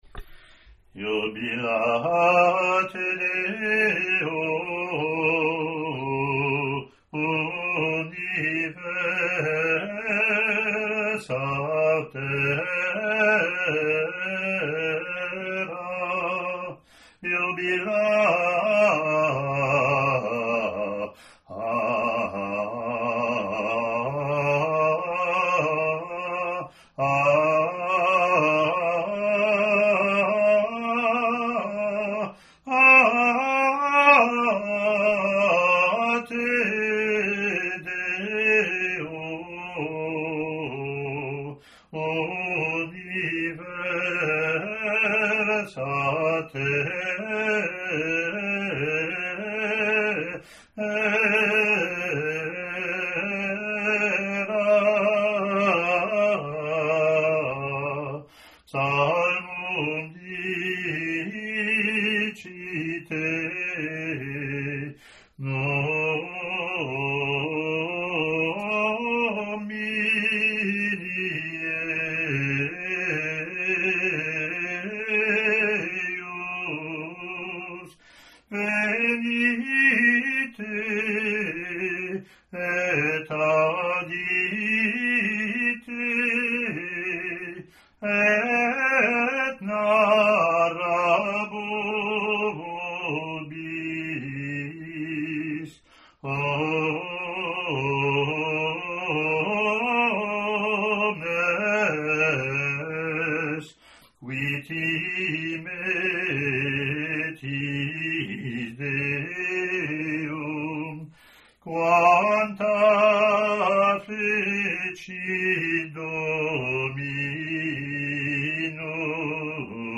Latin antiphon)